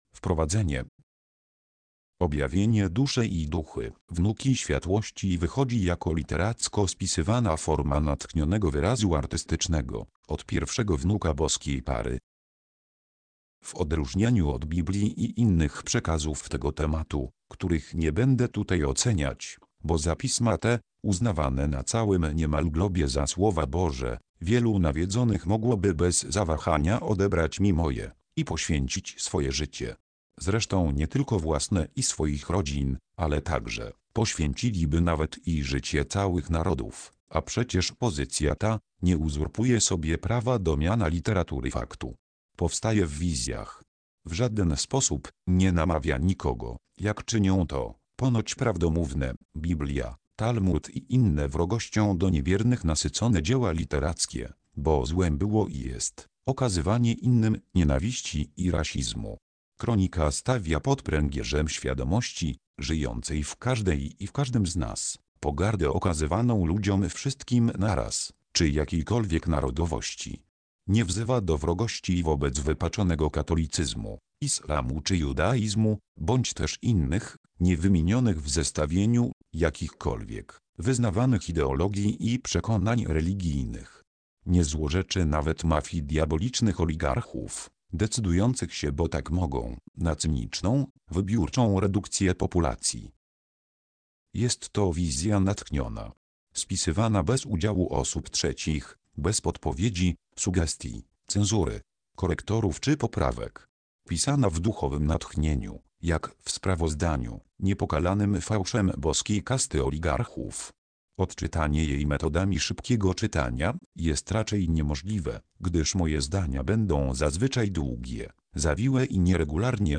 Audio version of 001 Wprowadzenie do Kroniki Źródła
Każdy z nich ma wersję audio, czytaną przez lektora.